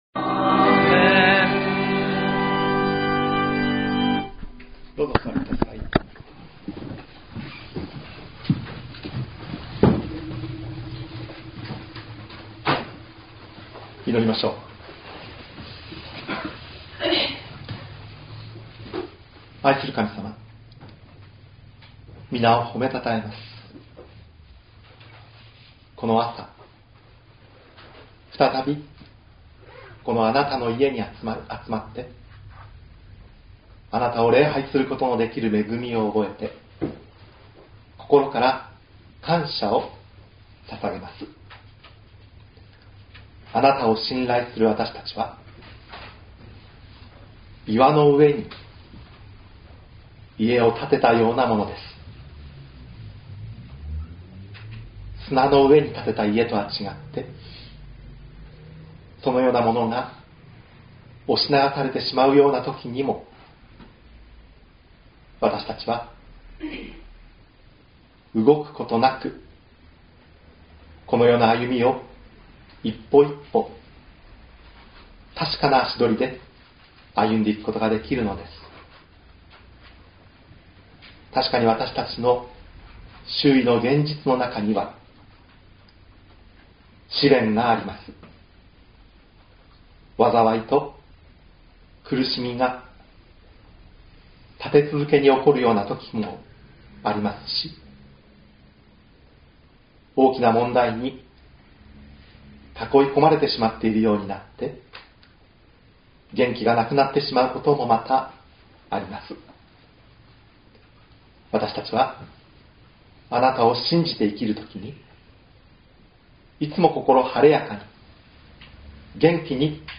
今日は伊丹教会・西谷伝道所講壇交換日で す 190505_001(変換後
.mp3 ←クリックして説教をお聴きください。使徒言行録２章22～28 2019年05月06日